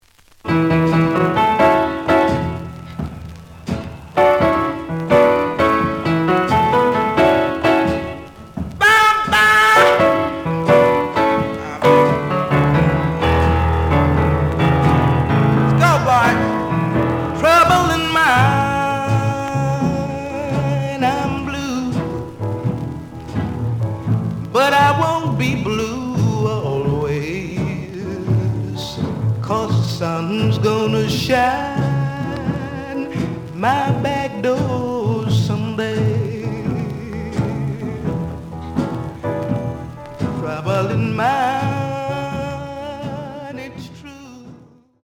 The audio sample is recorded from the actual item.
●Format: 7 inch
●Genre: Vocal Jazz